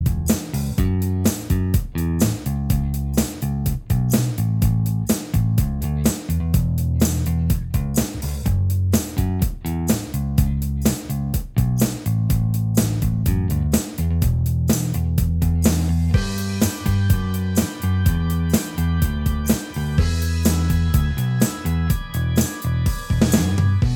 Minus Guitars Indie / Alternative 4:02 Buy £1.50